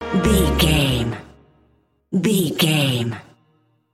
Epic / Action
Aeolian/Minor
E♭
drum machine
synthesiser
hip hop
Funk
energetic
bouncy
Triumphant
funky